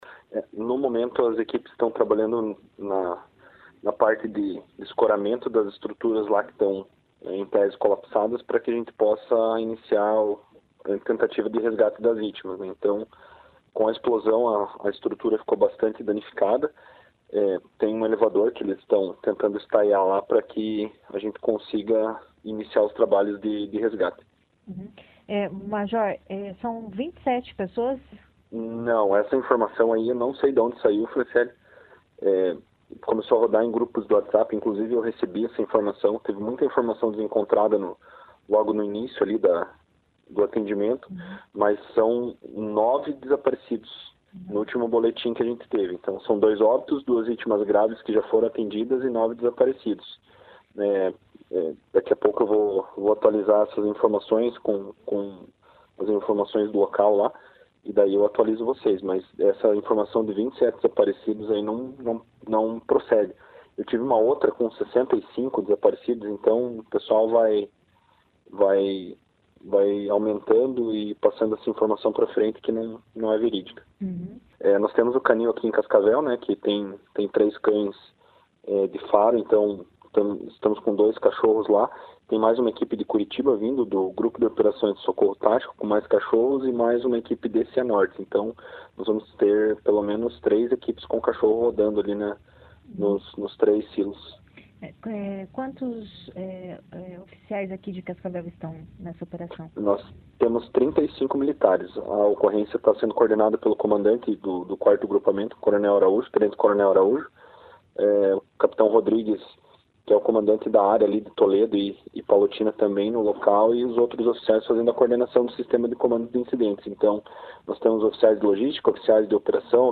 Major do Corpo de Bombeiros Militar fala sobre os atendimentos em Palotina